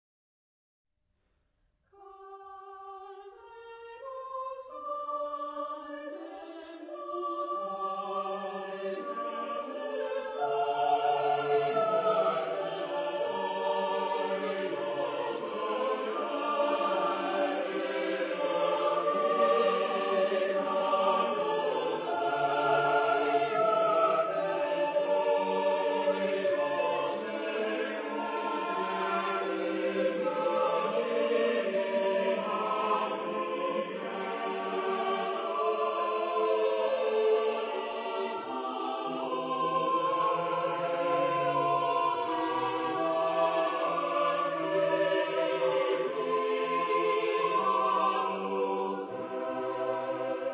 Epoque: 19th century
Genre-Style-Form: Sacred ; Romantic
Type of Choir: SSAATTBB  (8 mixed voices )
Tonality: G minor